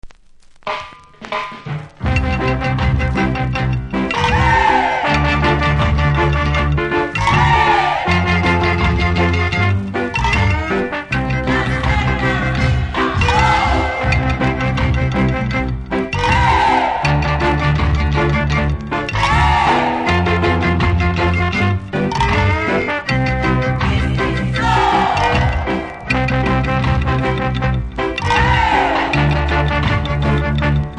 うすキズ多めですが音は良好なので試聴で確認下さい。